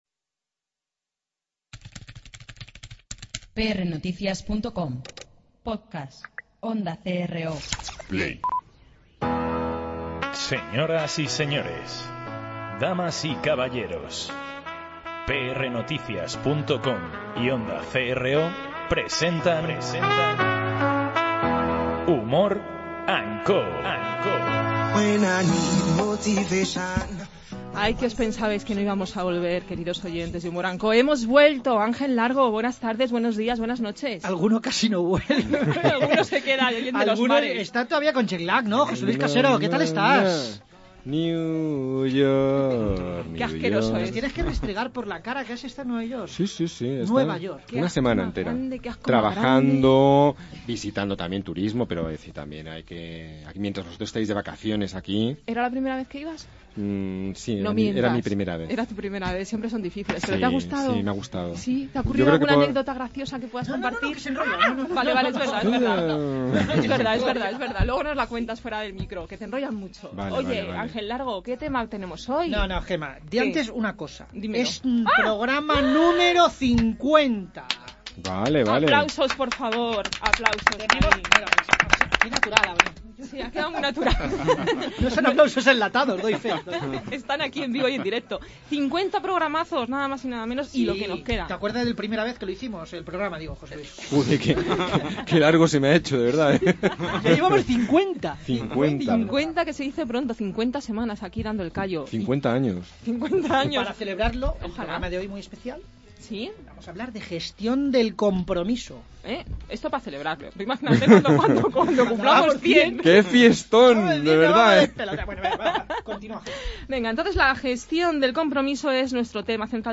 Tras este rato de risas aseguradas, nos ponemos algo más series, aunque no mucho, para recibir a nuestro entrevistado de la semana.